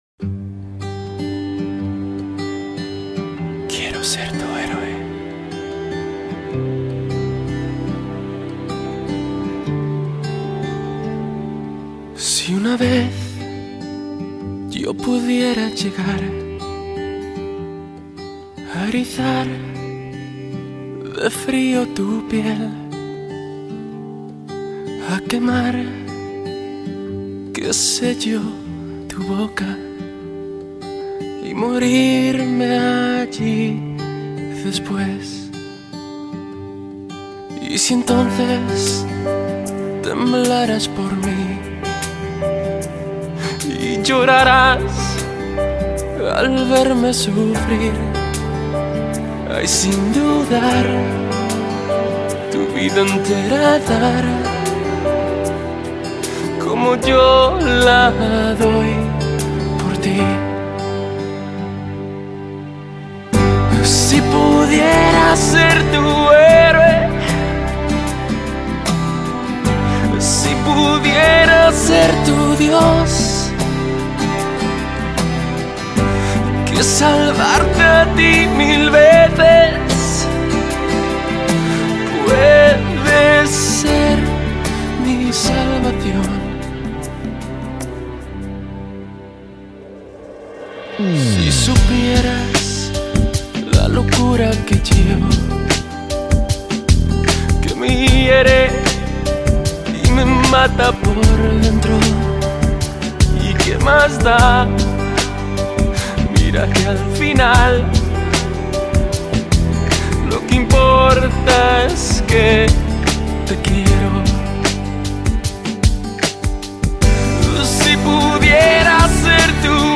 下面是我精选的十首经典西班牙语歌曲，曲风多为慢板抒情，希望大家喜欢。